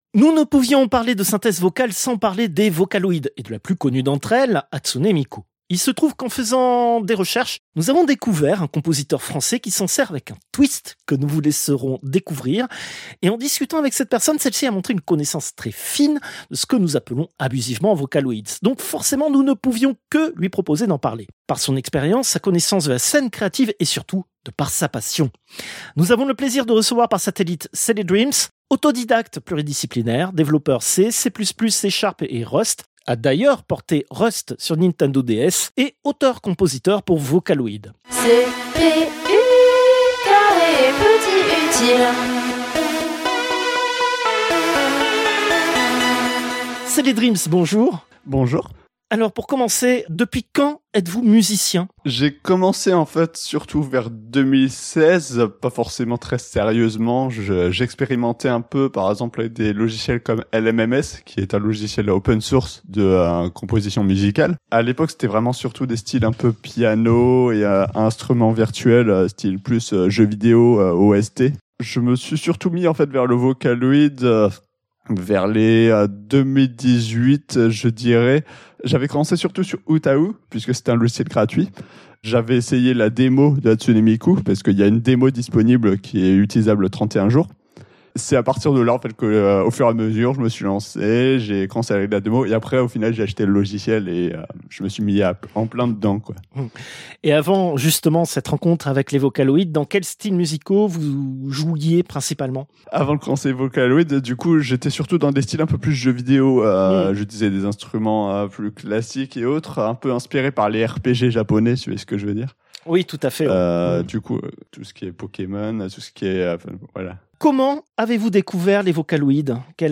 Interviewes
Interview diffusée dans les émissions CPU release Ex0224 : Synthèse vocale, première partie et CPU release Ex0225 : Synthèse vocale, seconde partie